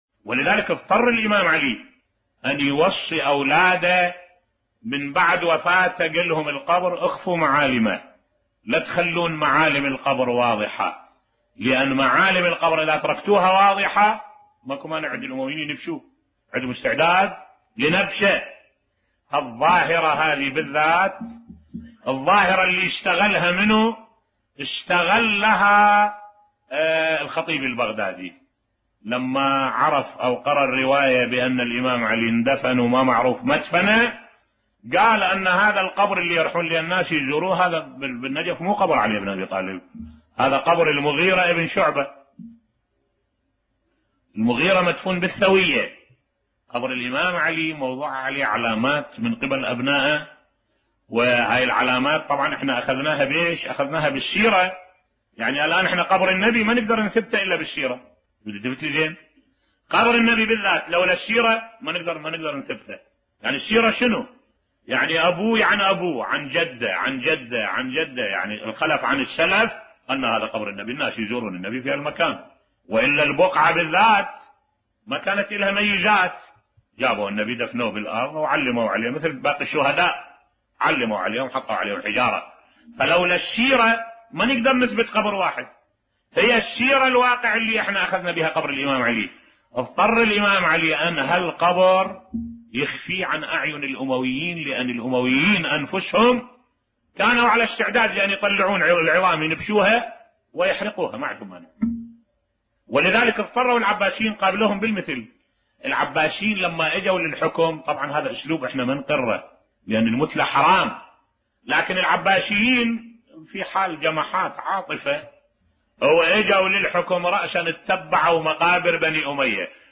ملف صوتی حقارة الأمويين في نبش القبور بصوت الشيخ الدكتور أحمد الوائلي